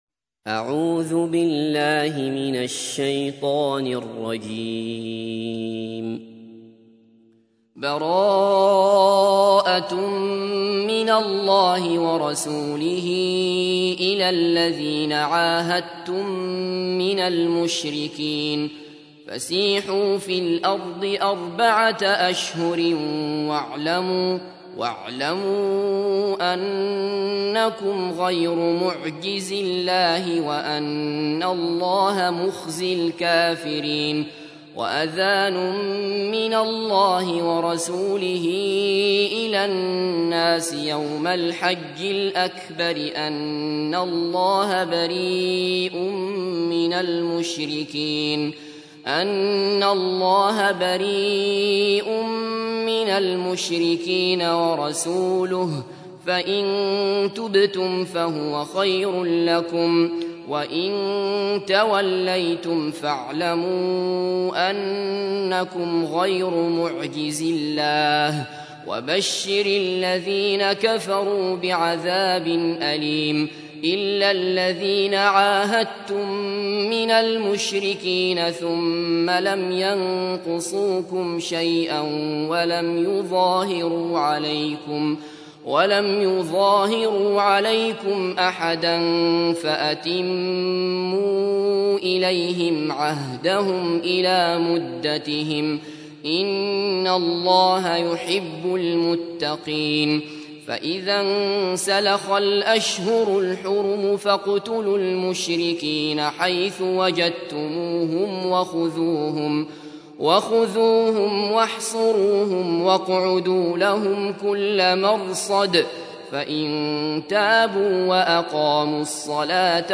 تحميل : 9. سورة التوبة / القارئ عبد الله بصفر / القرآن الكريم / موقع يا حسين